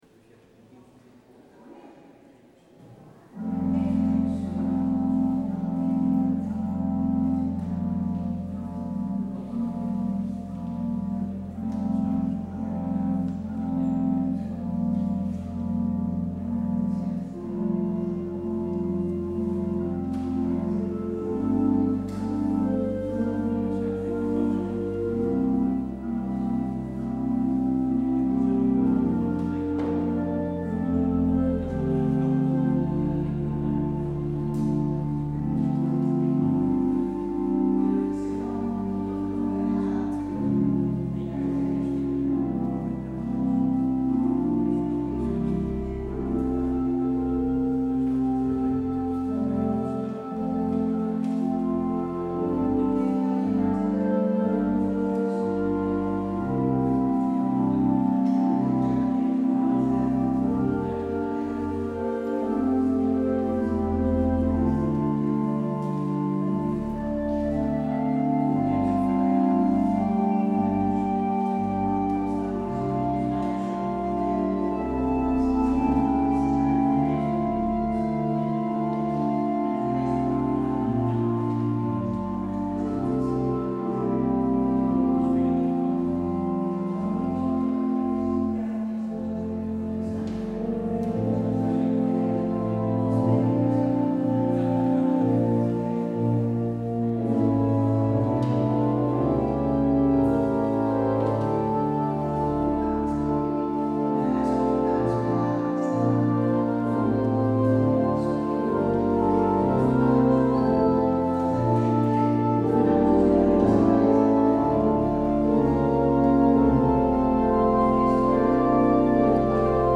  Luister deze kerkdienst hier terug